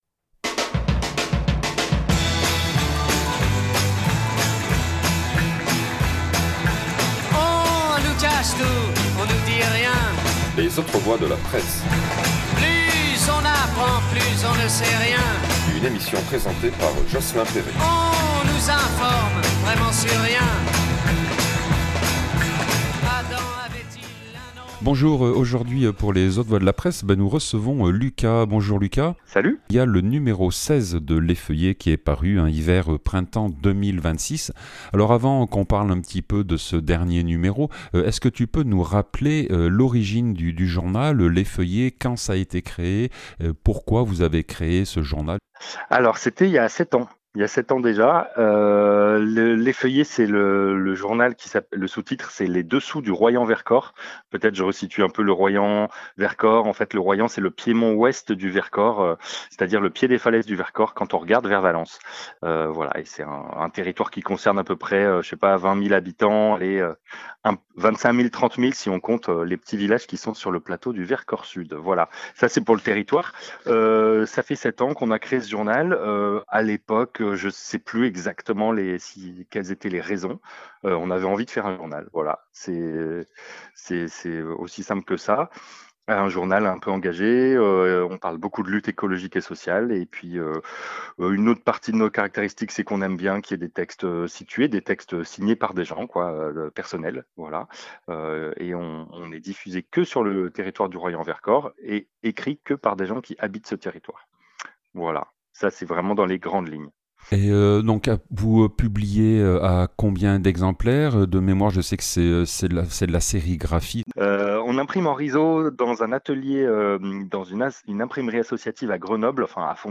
Rencontre dans un parc de Romans (26)